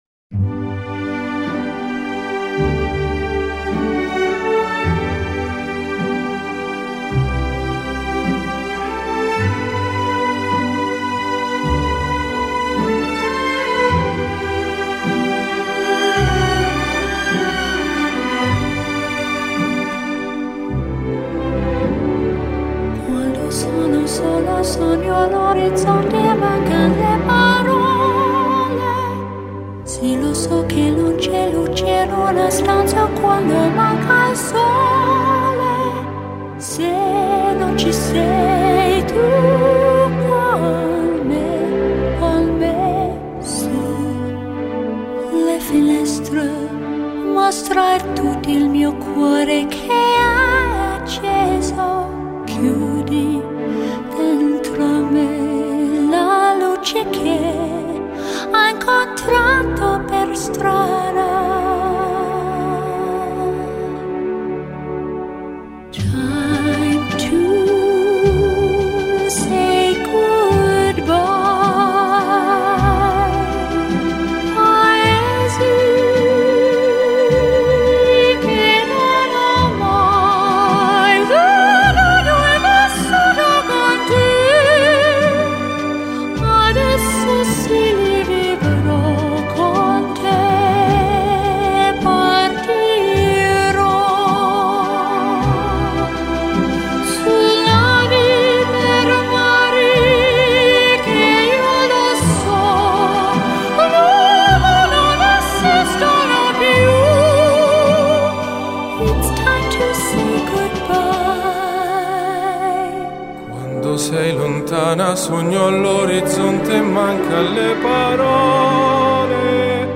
приятная мелодия (закрыта)